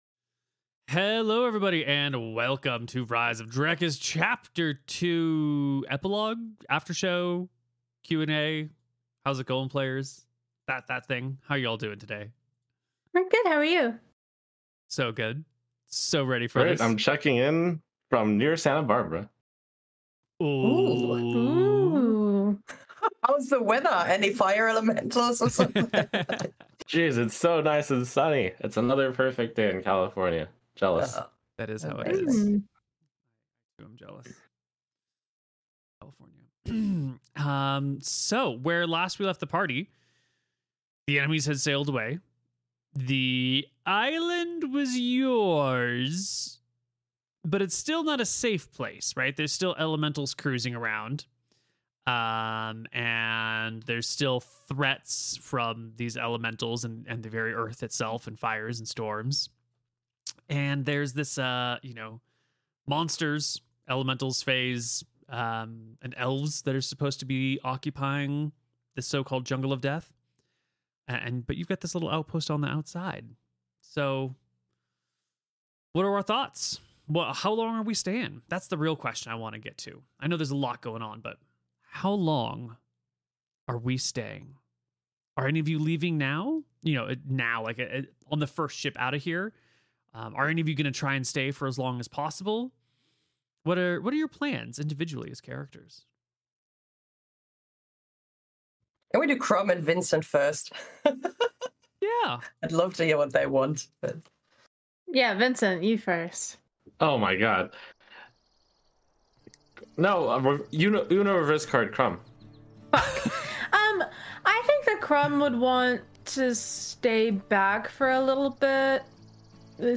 Format: Audio RPG
Voices: Full cast
Soundscape: Voices only